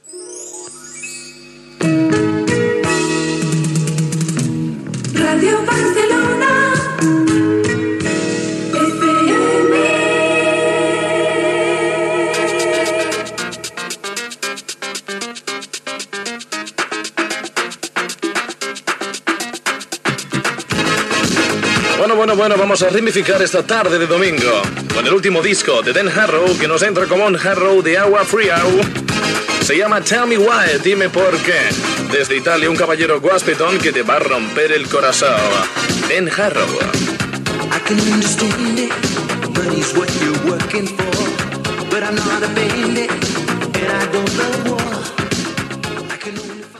Indicatiu de la ràdio i presentació d'un tema musical
Musical